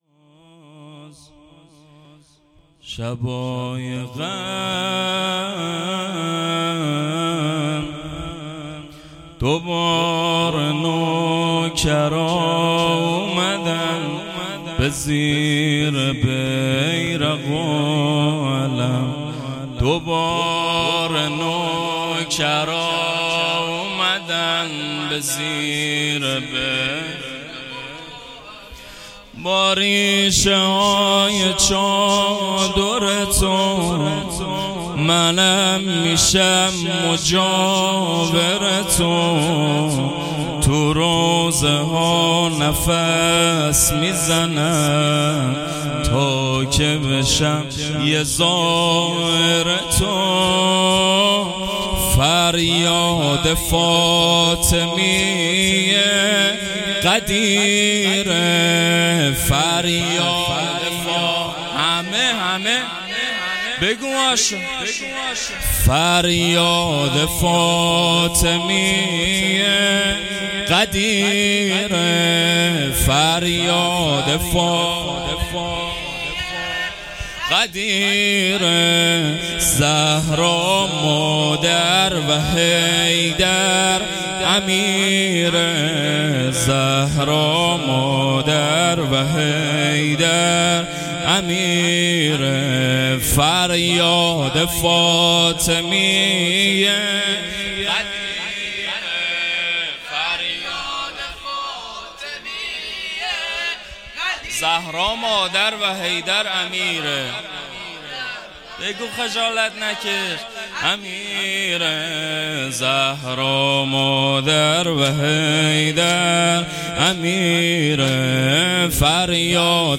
زمینه اول فریاد فاطمیه غدیره